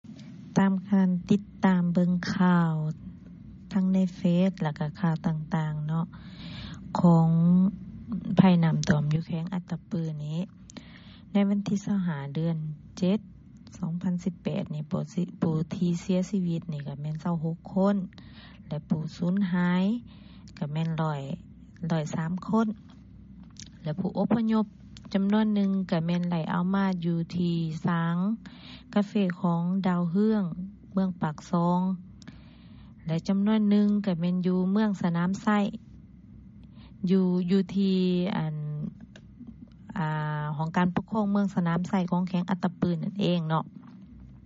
ນາງກ່າວຕໍ່ ວີໂອເອ ພາກພາສາລາວ ວ່າ:
ເຊີນຟັງສຽງ ຂອງປະຊາຊົນ ທ່ານນຶ່ງ ຢູ່ເມືອງປາກເຊ